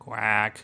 specialquack.mp3